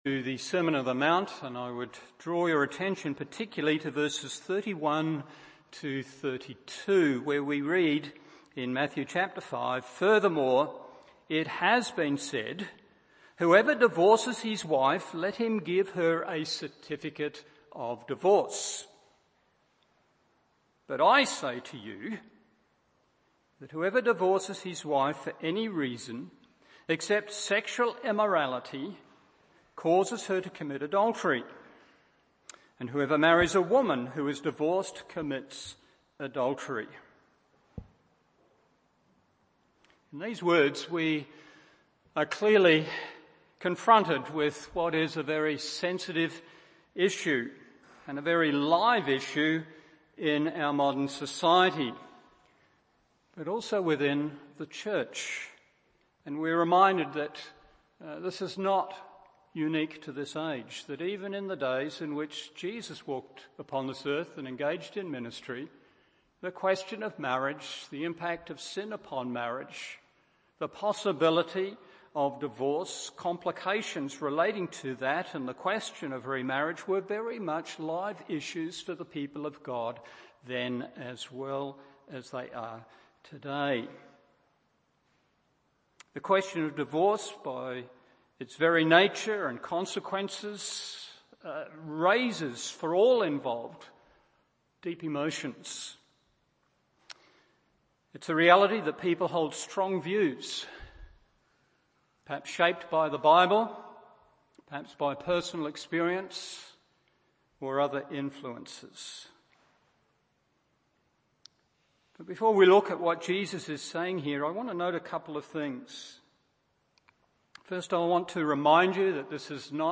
Morning Service Matt 5:31-32 1.